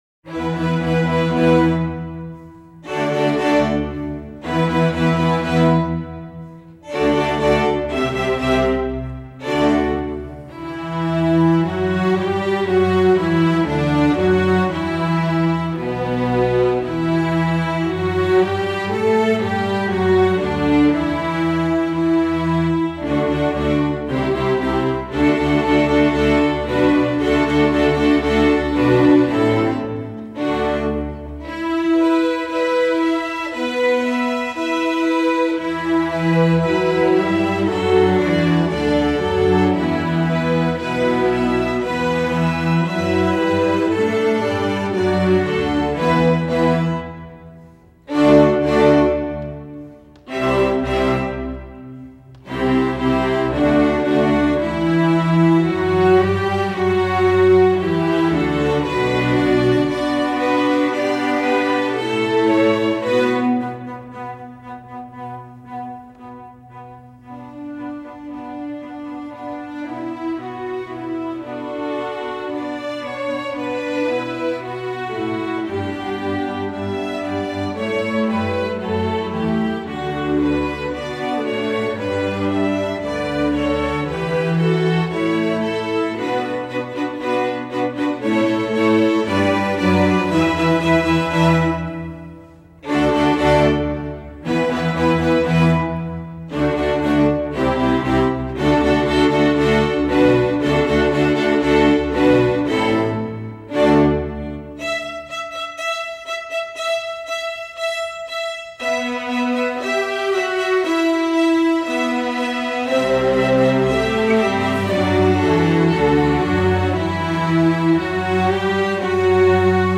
Voicing: String Orchestra S